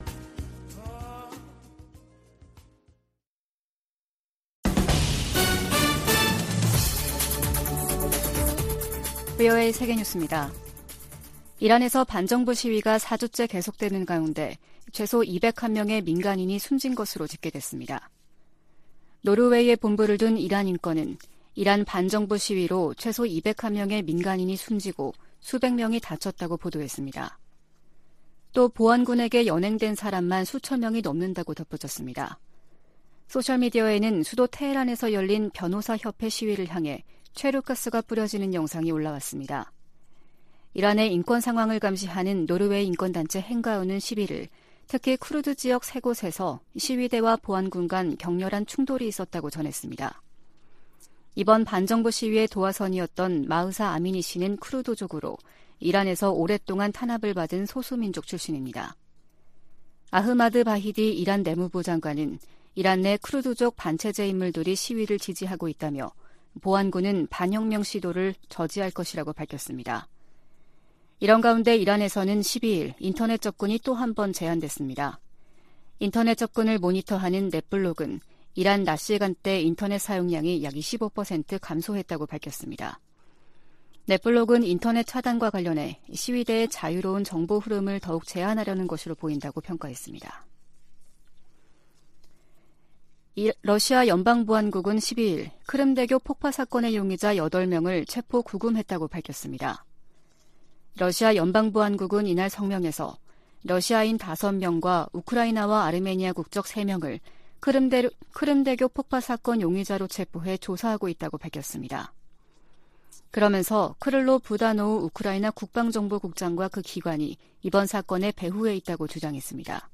VOA 한국어 아침 뉴스 프로그램 '워싱턴 뉴스 광장' 2022년 10월 13일 방송입니다. 한국 일각에서 전술핵 재배치 주장이 나오는 데 대해 백악관 고위 당국자는 비핵화 목표를 강조했습니다. 북한이 최근 '전술핵 운용부대 훈련'을 전개했다며 공개한 사진 일부가 재활용된 것으로 파악됐습니다. 미국 정부가 핵을 포함한 모든 범위의 확장 억지 공약을 재확인했습니다.